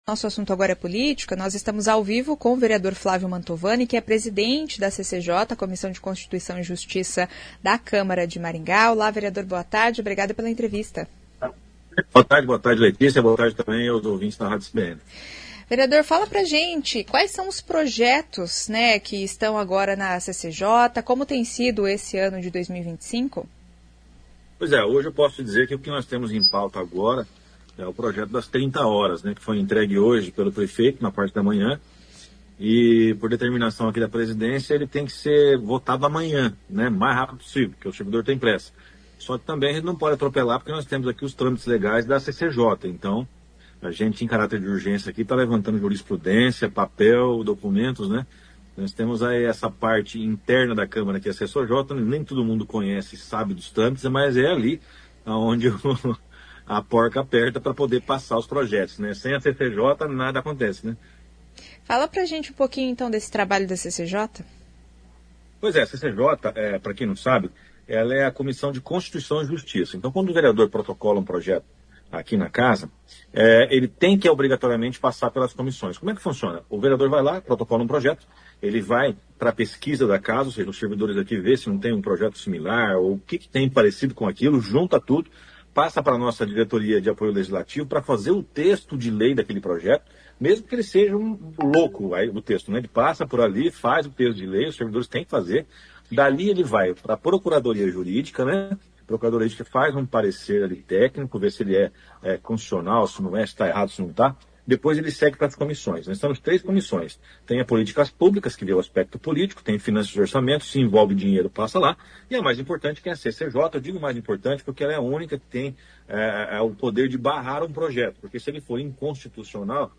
Mantovani também falou em entrevista sobre a comissão que analisa a PPP da iluminação pública.